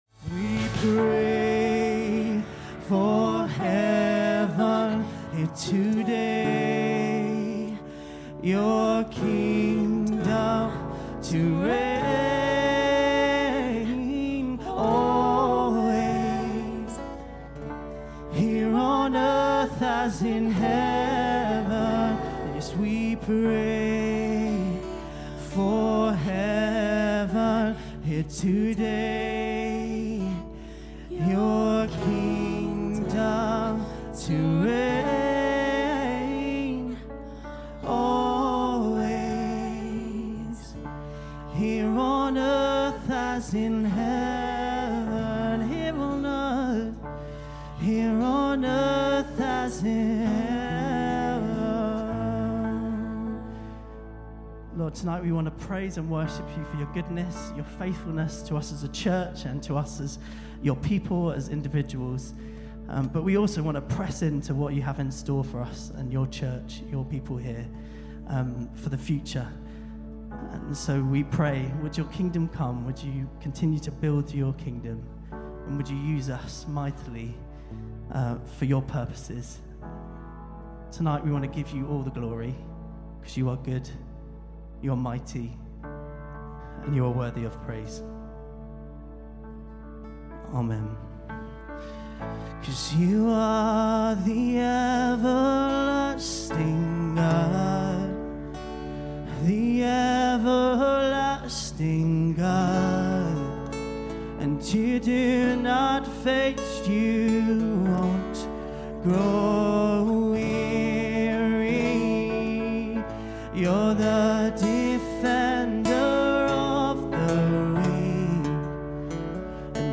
Maybridge Community Church's Lifelines celebration with worship, stories, prayers and thanksgiving for our 70th Anniversary.